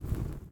Flare04.ogg